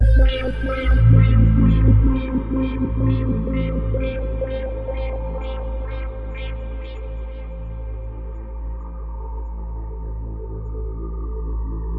公告相声快板
描述：我把自己说话的声音录了大约5秒钟。然后我对它进行了时间压缩，并在它上面放了一个相位器，还有混响。
标签： 公告 移相器
声道立体声